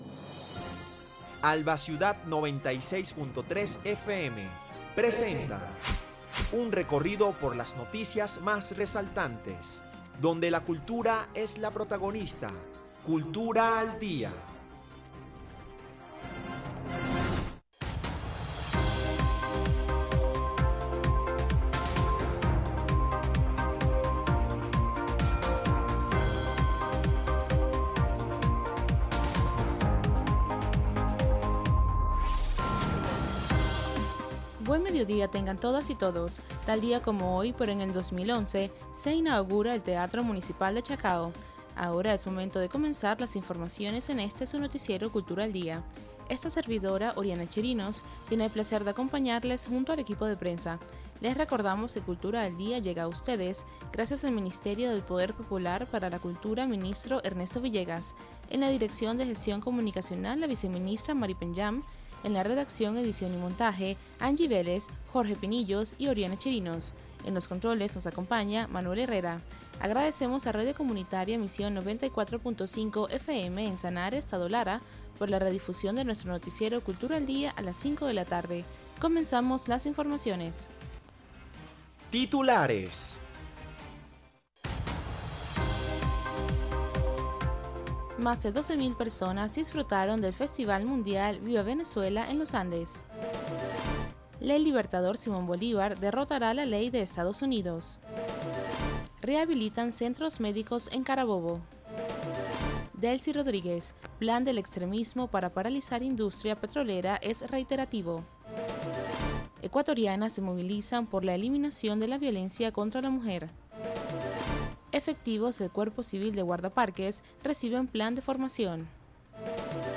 Noticiero de Alba Ciudad. Recorrido por las noticias más resaltantes del acontecer nacional e internacional, dando prioridad al ámbito cultural.